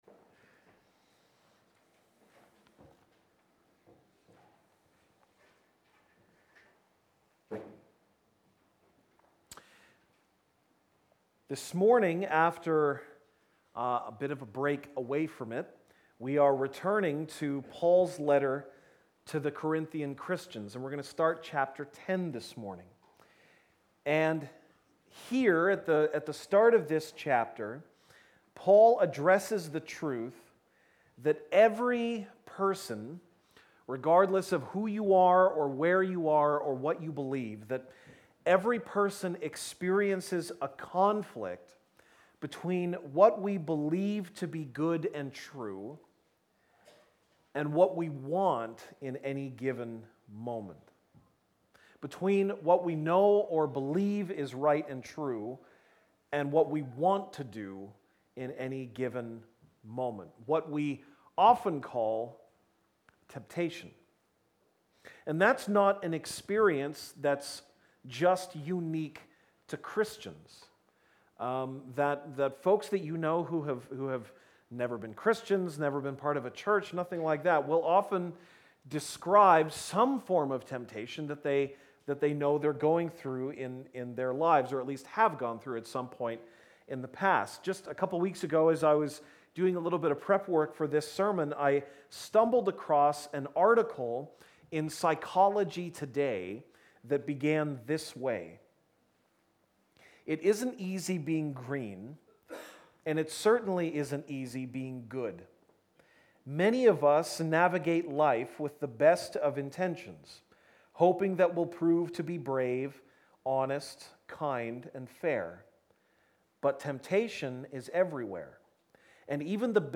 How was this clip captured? March 31, 2019 (Sunday Morning)